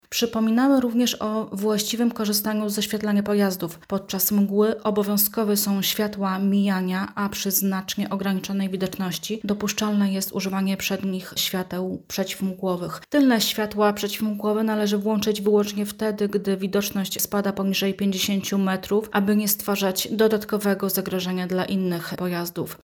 mówi podkomisarz